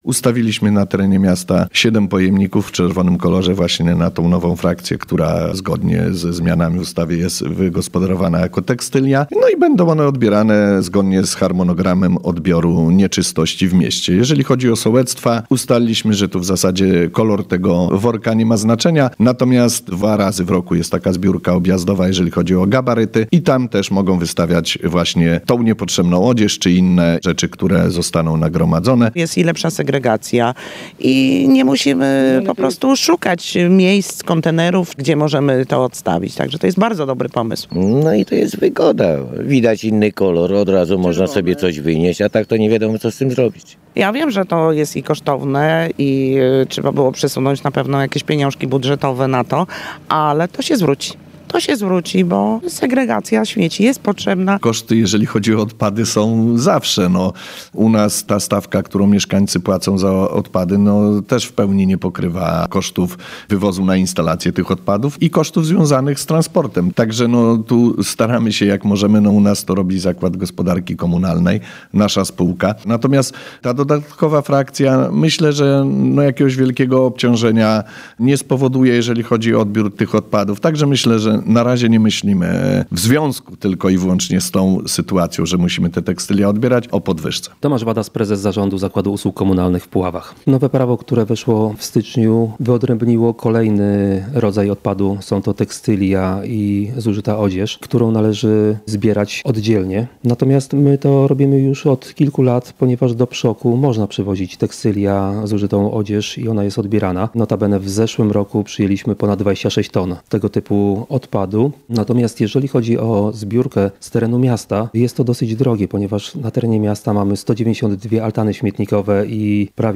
– My w różnych częściach miasta postawiliśmy pojemniki na tego typu odpady – mówi burmistrz Poniatowej, Paweł Karczmarczyk.